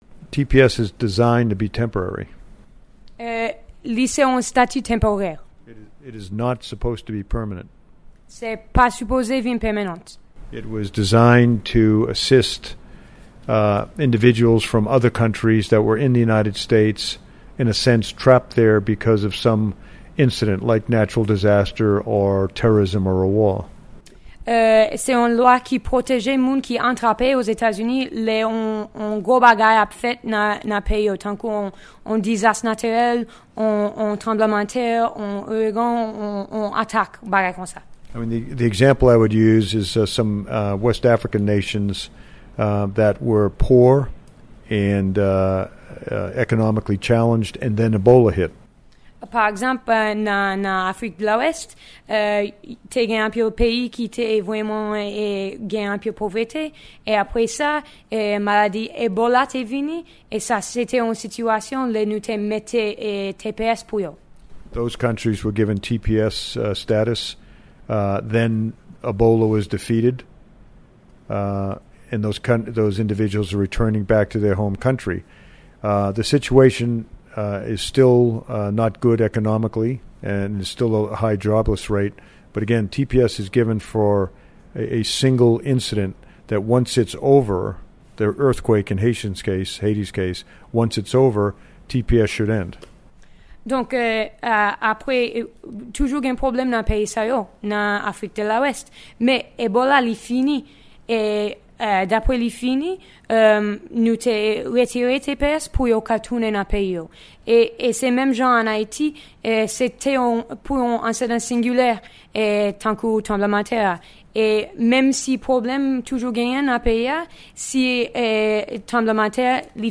Ekstrè entèvyou Minis John Kelly